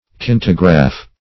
kinetograph \ki*ne"to*graph\, n. [Gr.